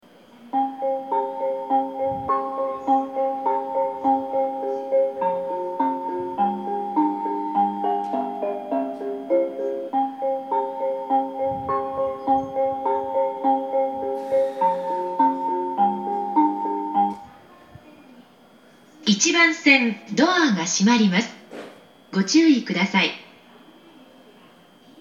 久喜駅　Kuki Station ◆スピーカー：小VOSS,ユニペックス箱型
1番線発車メロディー